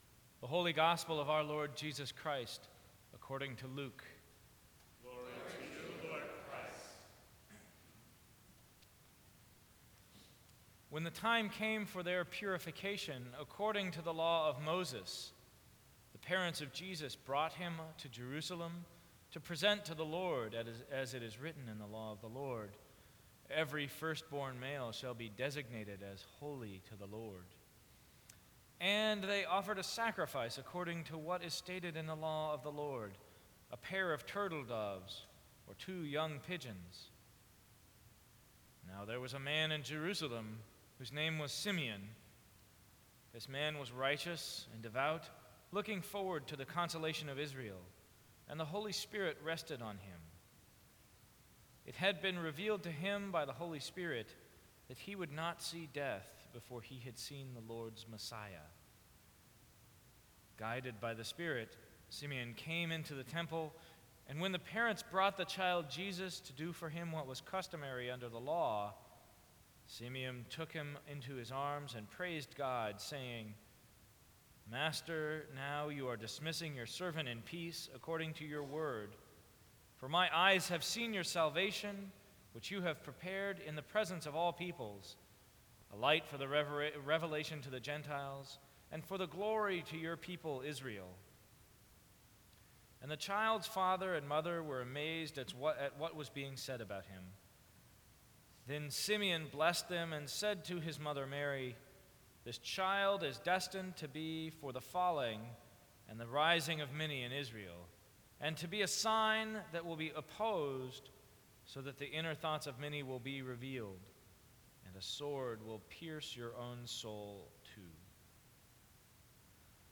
Sermons from St. Cross Episcopal Church February 2, 2013.